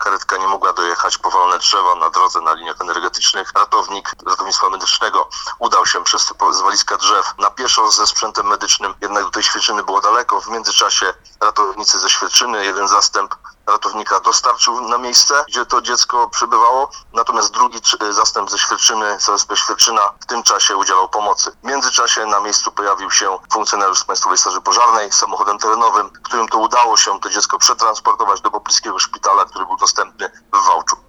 – mówi Paweł Dymecki, Komendant powiatowy Państwowej Straży Pożarnej w Drawsku Pomorskim.